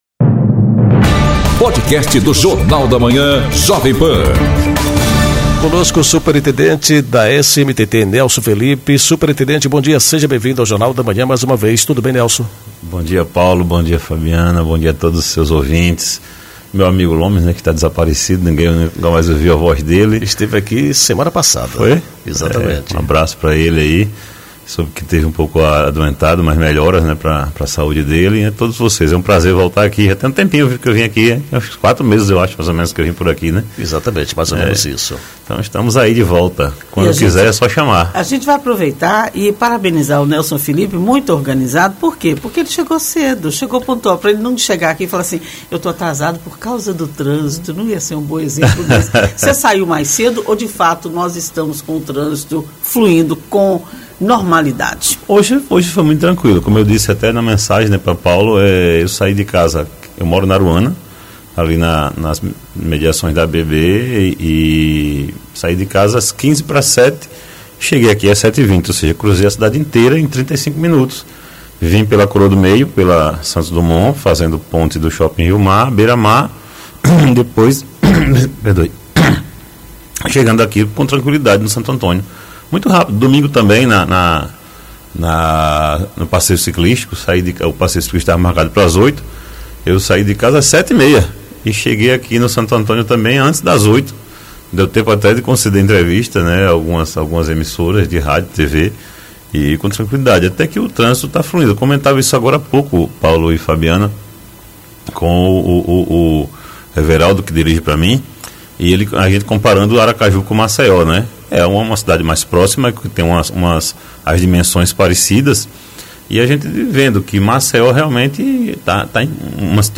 Entrevista com o superintendente da SMTT, Nelson Felipe. Ele fala sobre alterações no trânsito de Aracaju e a campanha de educação.